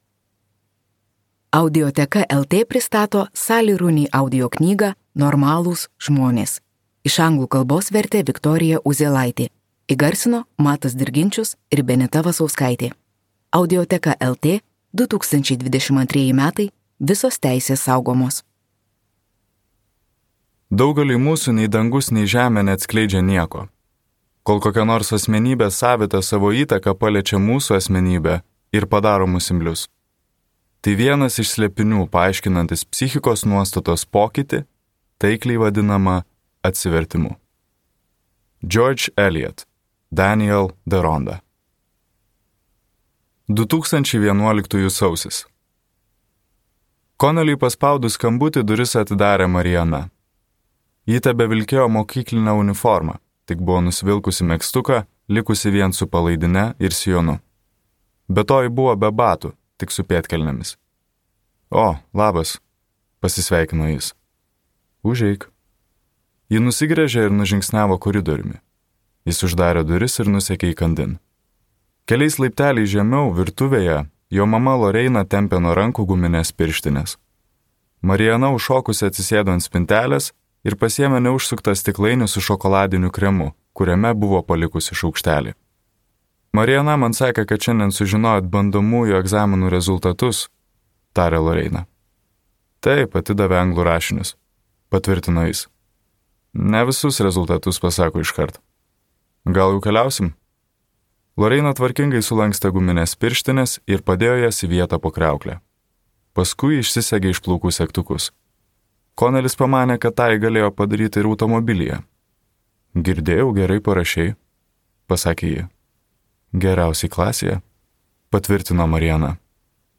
Normalūs žmonės | Audioknygos | baltos lankos